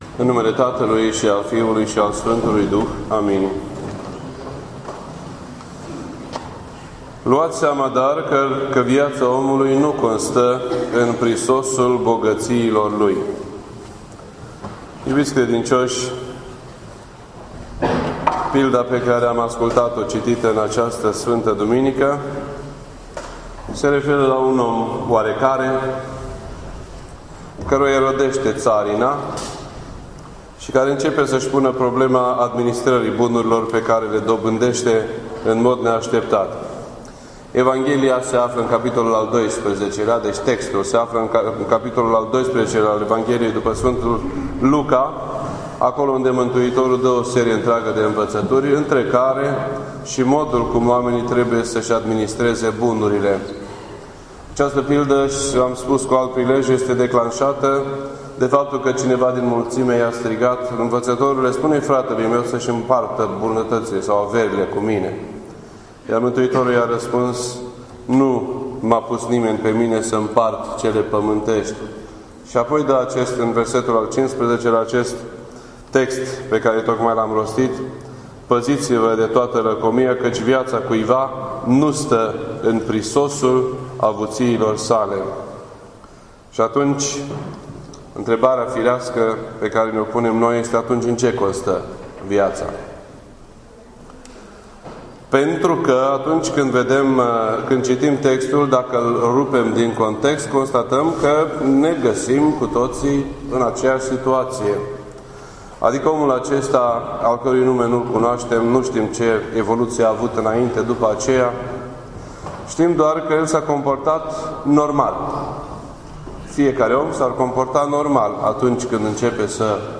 This entry was posted on Sunday, November 23rd, 2014 at 9:02 PM and is filed under Predici ortodoxe in format audio.